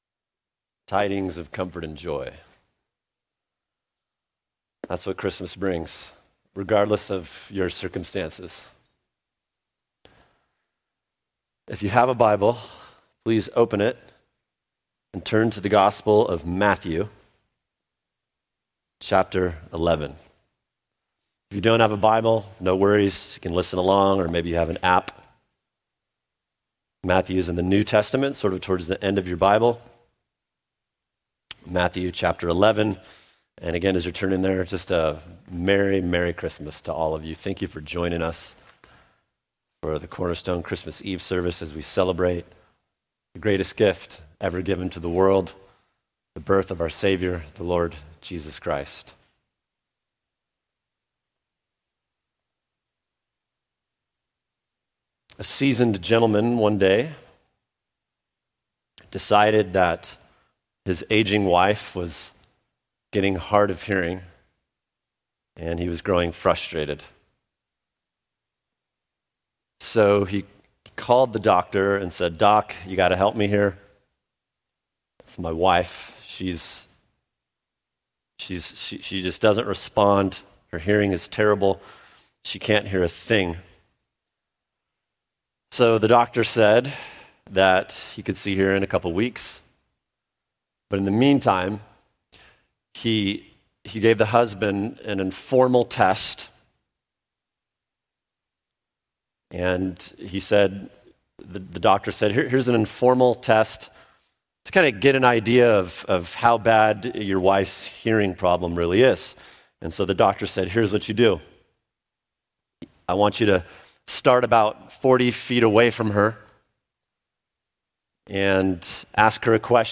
[sermon] The Rest of Christmas Matthew 11:28 | Cornerstone Church - Jackson Hole
12/24/23 (Christmas Eve Service)